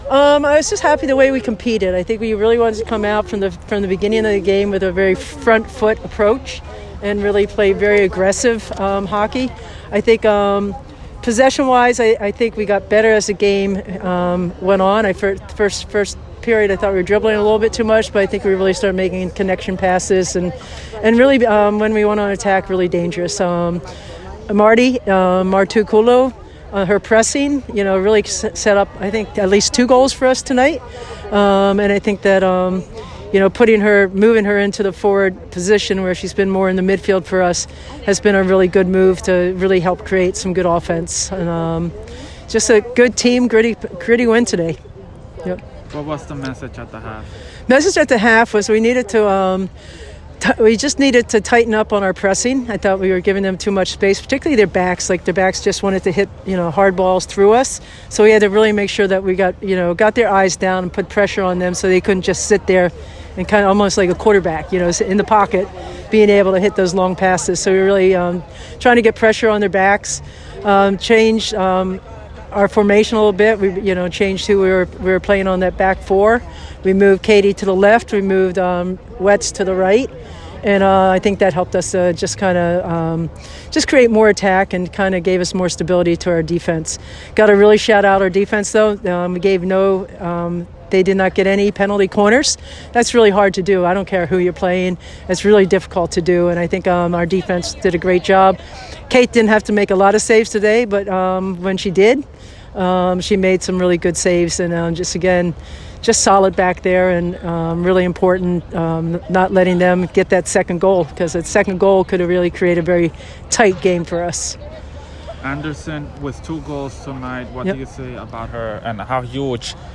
Colgate Postgame Interview